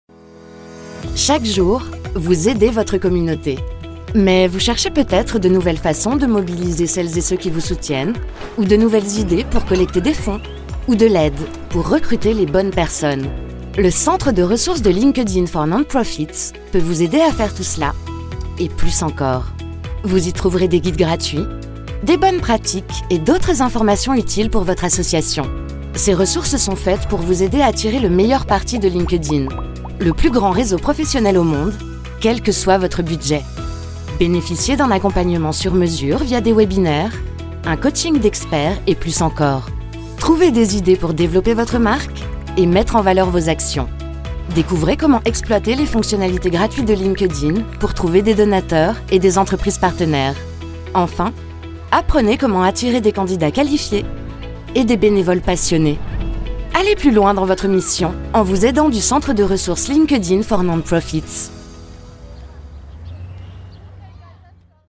Vidéos explicatives
Artiste inspirée, attentive, disponible et polyvalente, avec une voix grave-médium.
Cabine DEMVOX, micro Neumann TLM 103, Scarlett 4i4 et ProTools Studio sur un Mac M1